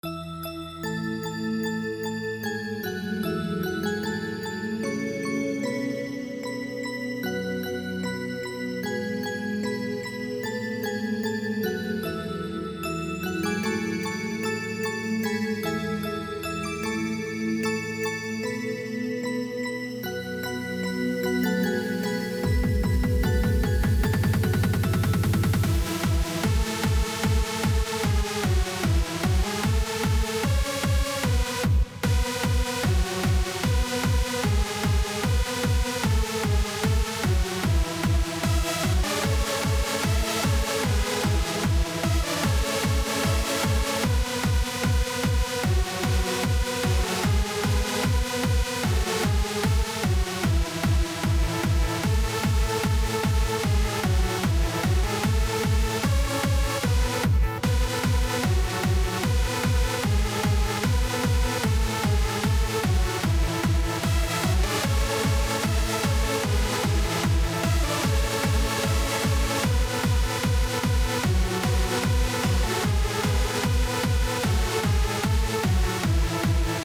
Hardstyle_Remix